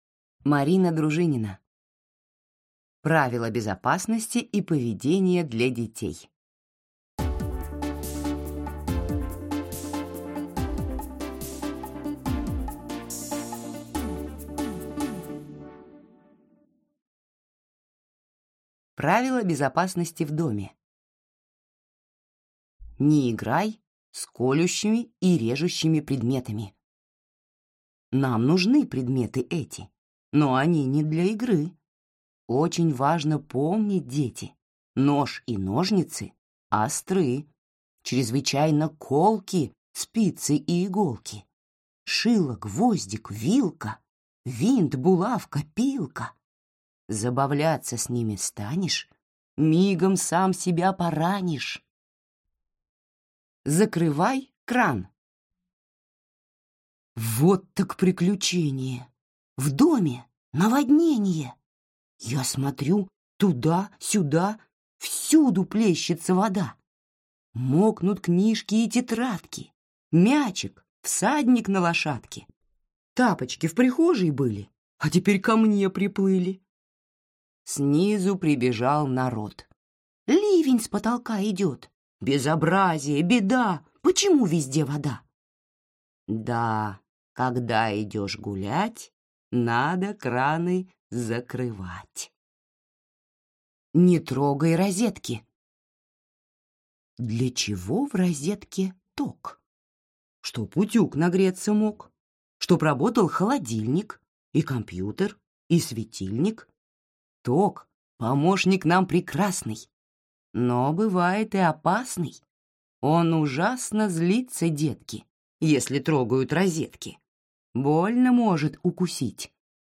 Аудиокнига Правила безопасности и поведения для детей | Библиотека аудиокниг